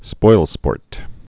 (spoilspôrt)